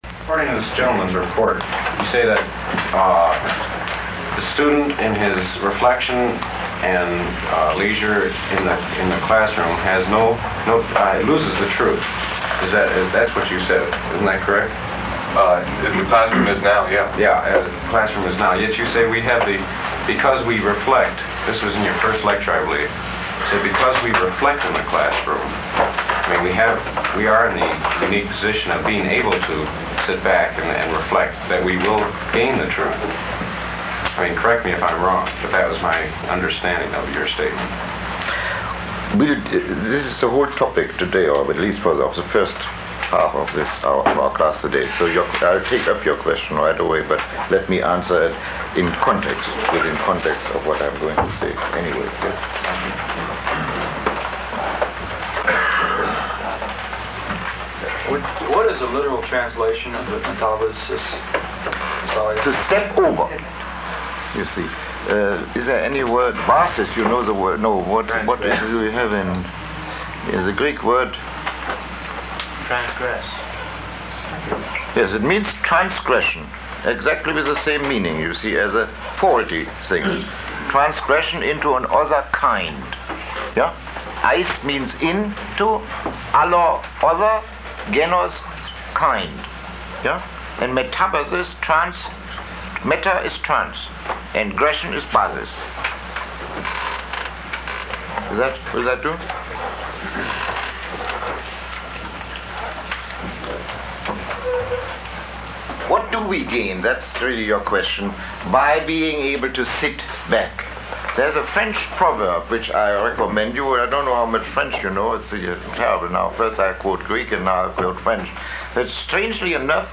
Lecture 06